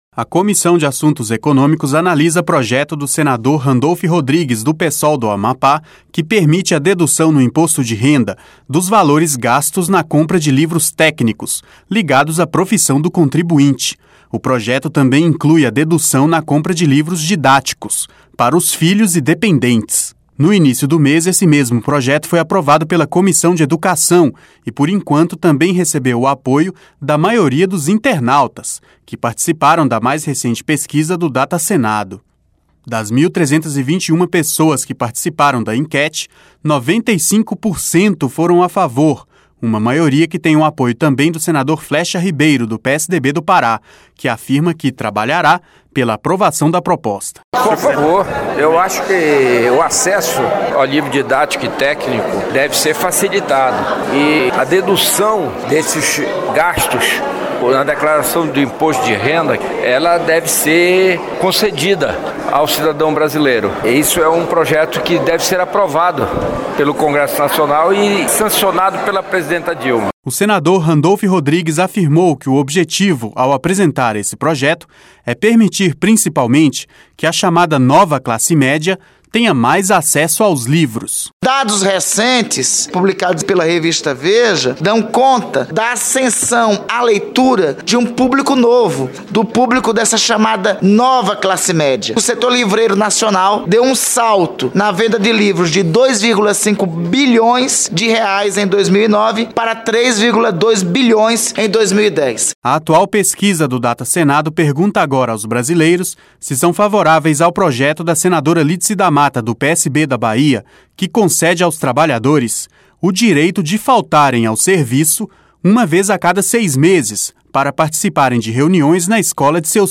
LOC: ESTES NÚMEROS SÃO DA MAIS RECENTE PESQUISA DO DATASENADO.